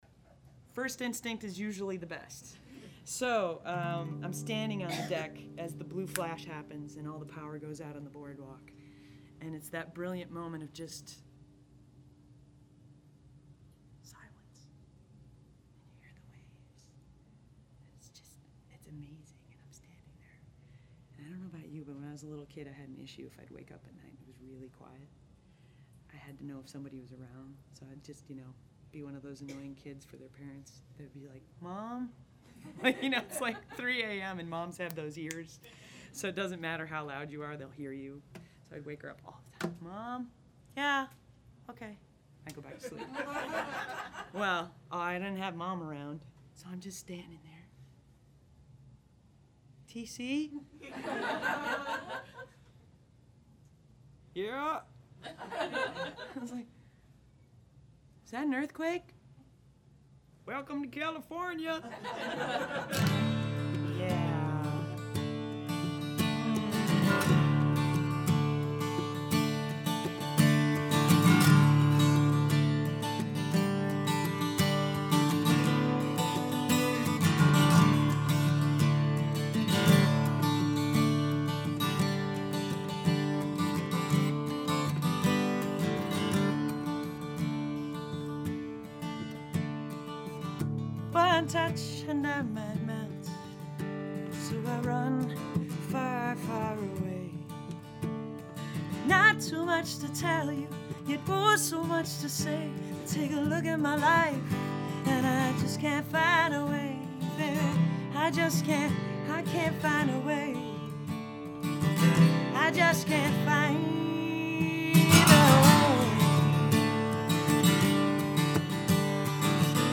I was pretty light on the NR - This is a very dynamic recording and it's going to stay that way.
Artifacts are (IMHO) light in all cases, but it's pretty clear to me that NR on each track is the right way to go.
nr_each_track_dry.mp3